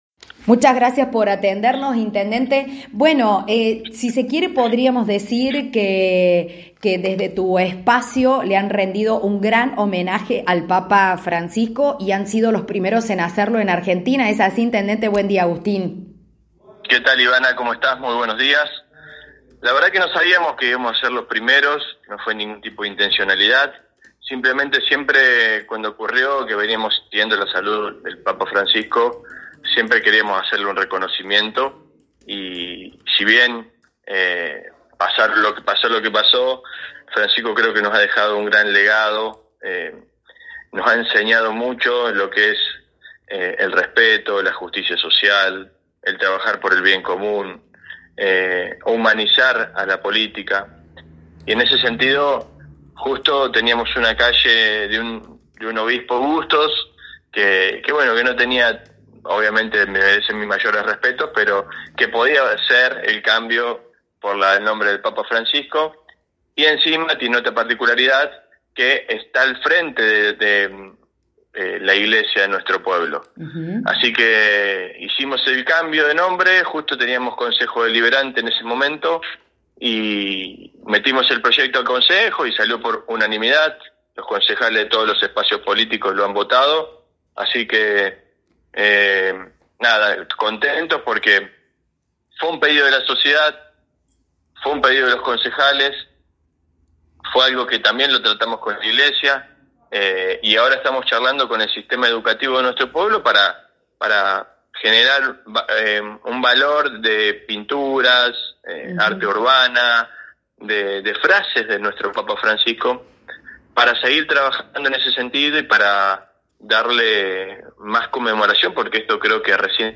En diálogo con nuestra radio el Intendente de Cruz Alta Agustín González, compartió detalles del homenaje que realizaron en la localidad al Papa Francisco, convirtiéndose en los primeros del país en dedicarle una calle.